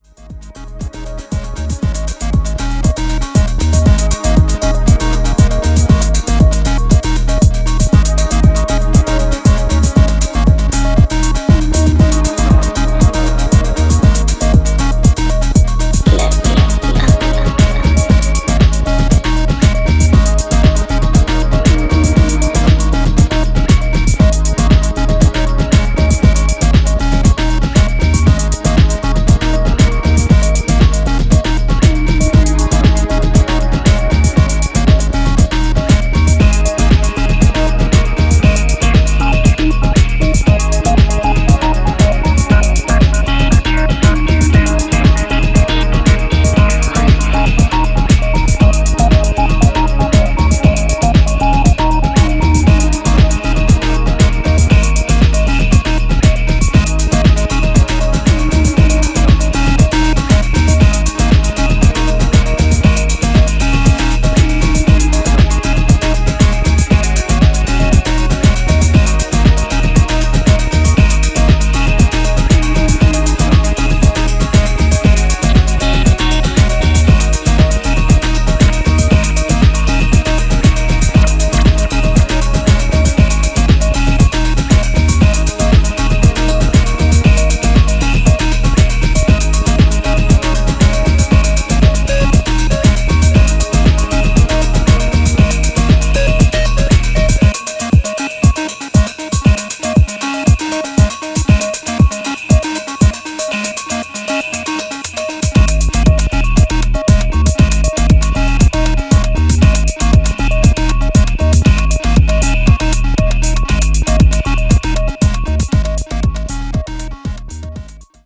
Five classic house tracks